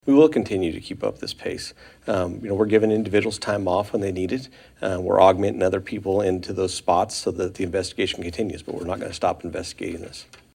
Moscow Police Chief Jim Fry says investigators will continue their investigative pace in an effort to solve the murders.